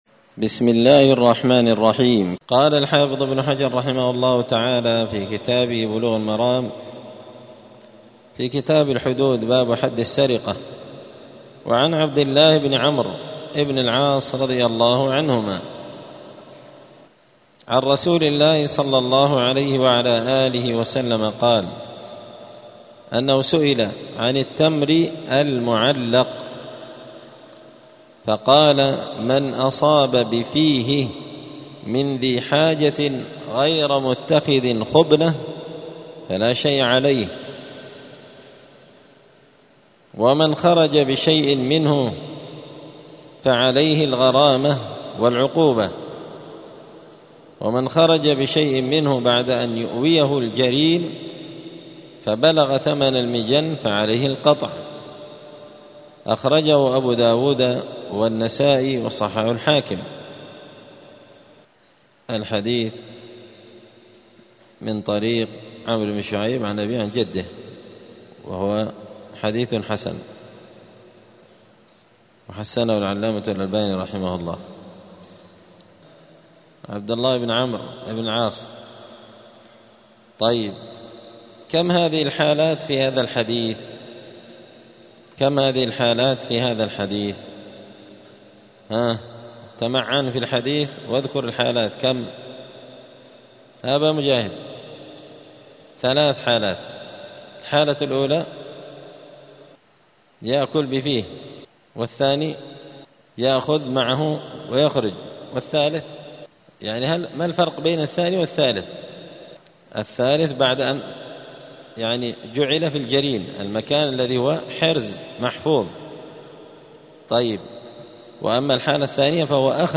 *الدرس السادس والعشرون (26) {باب حد السرقة اشتراط الحرز في وجوب القطع}*